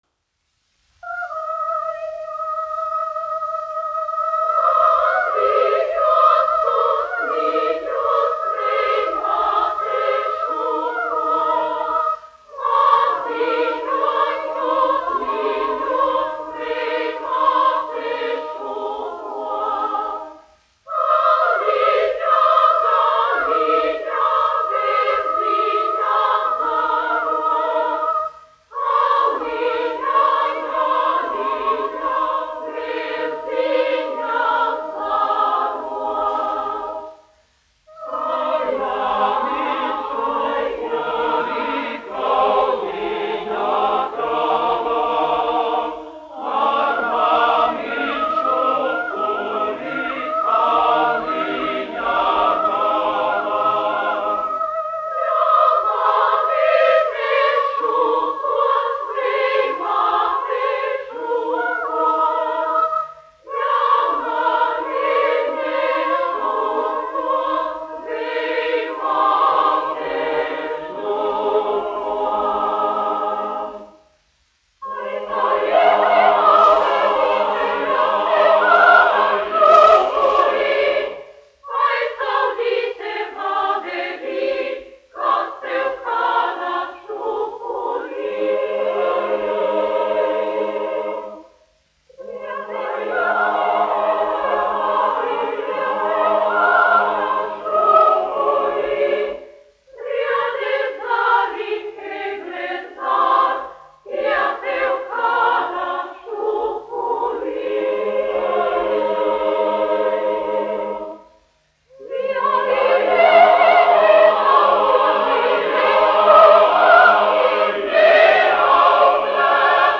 Jurjāns, Pāvuls, 1866-1948, aranžētājs
Latvijas Radio koris, izpildītājs
Kalniņš, Teodors, 1890-1962, diriģents
1 skpl. : analogs, 78 apgr/min, mono ; 25 cm
Latviešu tautasdziesmas
Kori (jauktie)
Skaņuplate